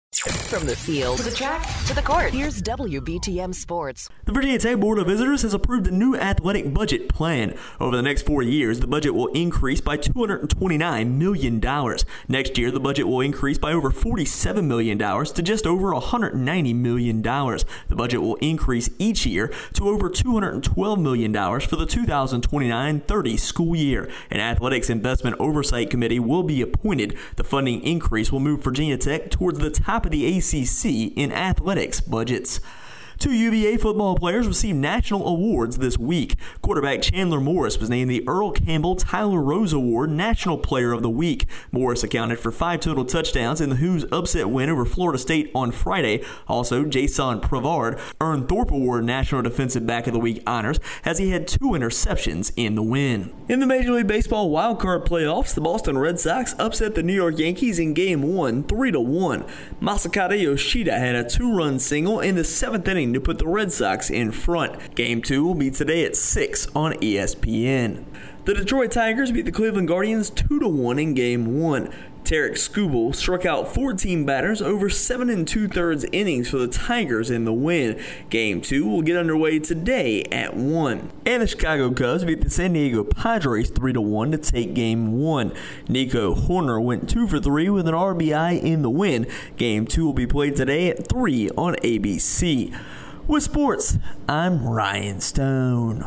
Virginia Tech Board of Visitors Approve Increase to Athletics Budget, Two UVA Players Receive National Weekly Awards and More in Our Local Sports Report